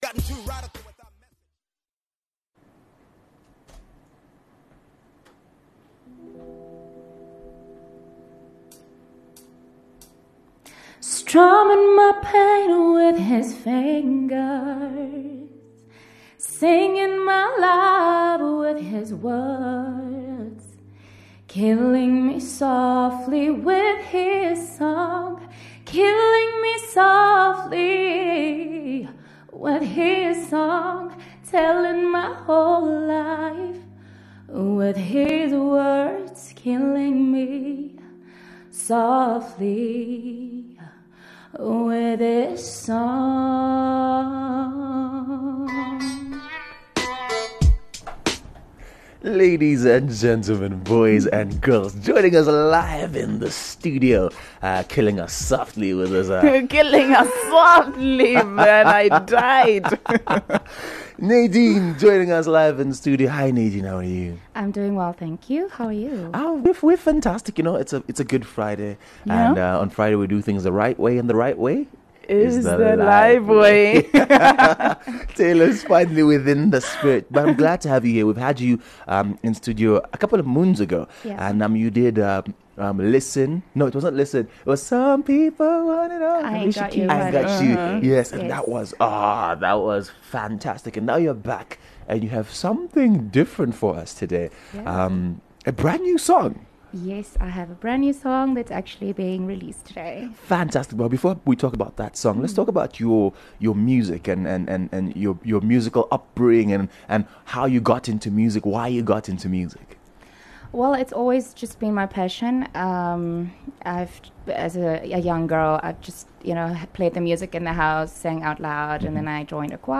in the fresh studio